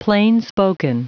Prononciation du mot plainspoken en anglais (fichier audio)
Prononciation du mot : plainspoken